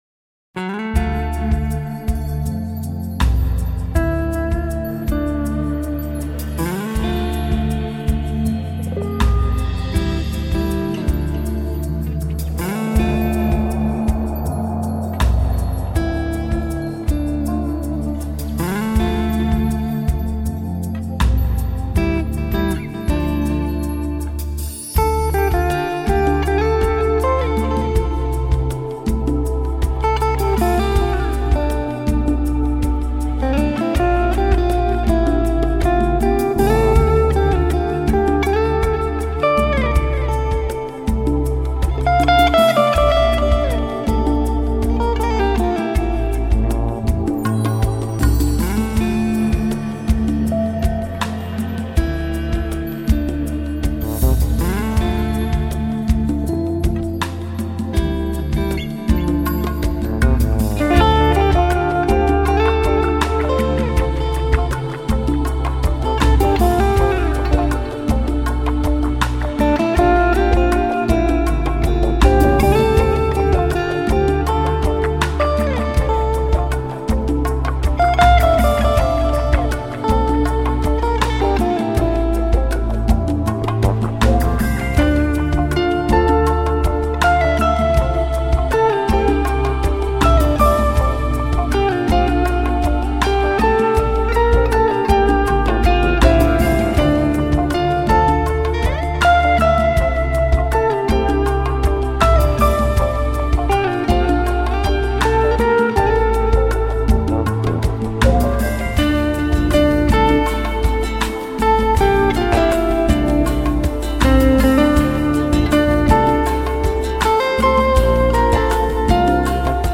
С музыкой романтично, с настроением..
Музыка прекрасно уносит в рассветную чувственную даль и романтично качает в небесных волнах!!!